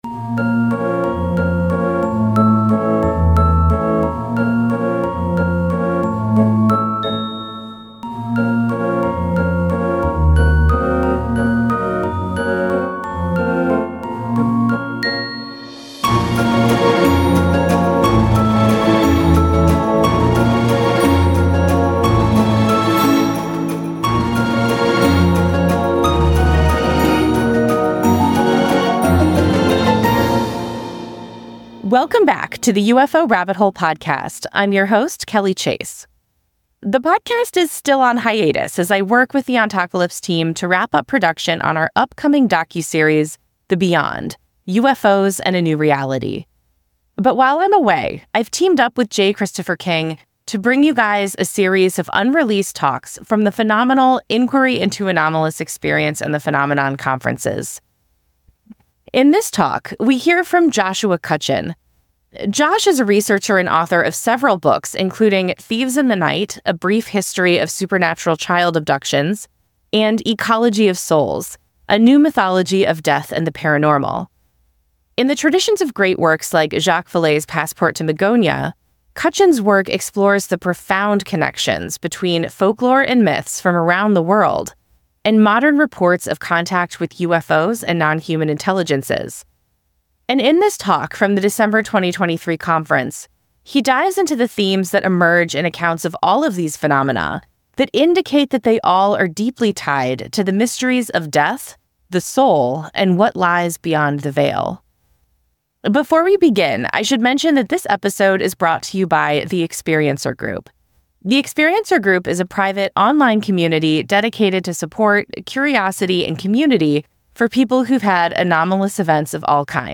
Social Sciences, Society & Culture, Science, Documentary